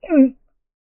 male_drown5.ogg